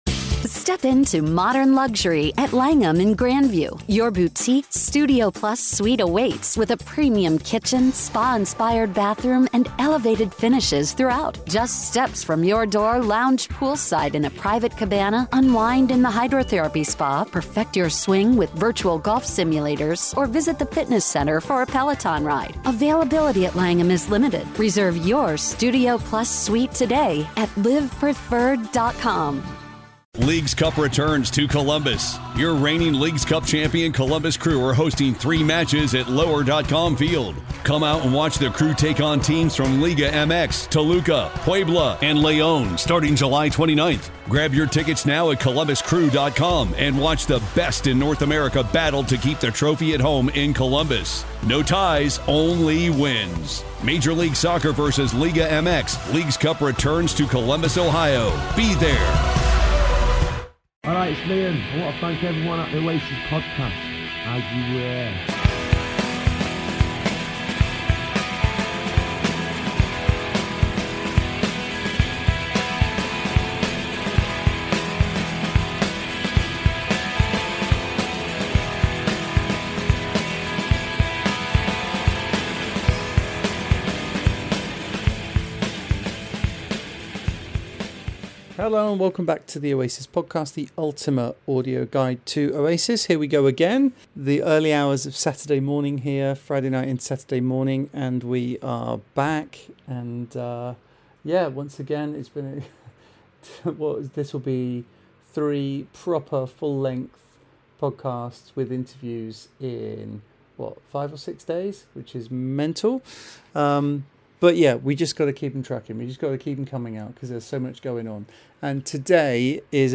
Headliner Embed Embed code See more options Share Facebook X Subscribe Hello and welcome back to the Oasis Podcast Today's episode features interviews with